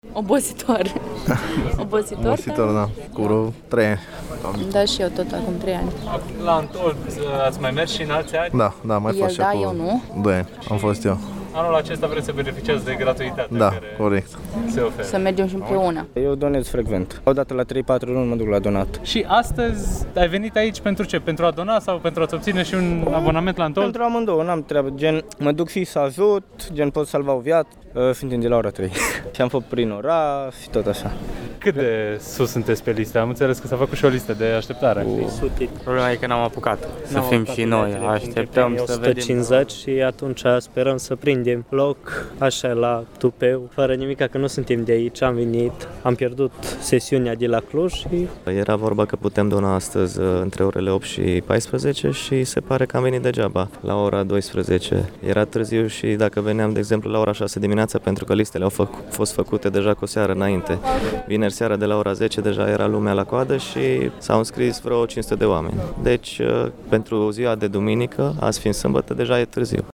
Cei prezenți astăzi în fața Teatrului Național din Tg. Mureș, locul de desfășurare al caravanei de donare de sânge, spun că organizatorii au întocmit liste de așteptare de circa 500 de persoane, programate să doneze inclusiv mâine: